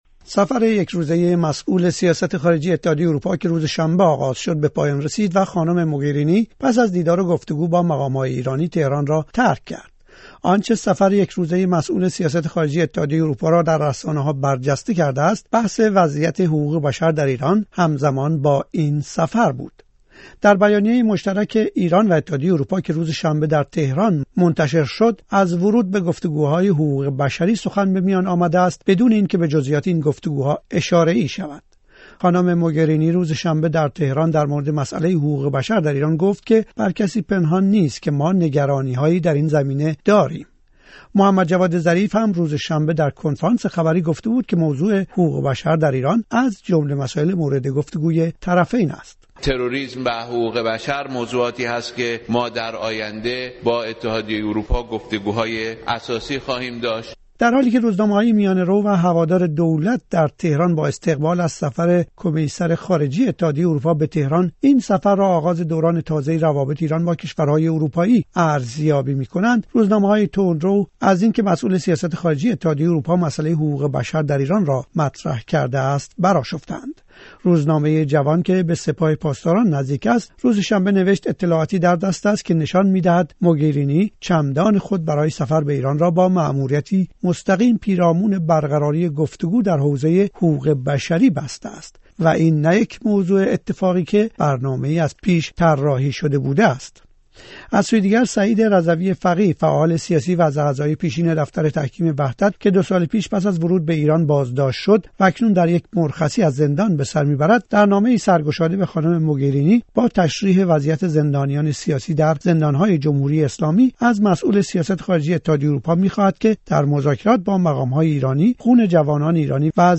سیاسی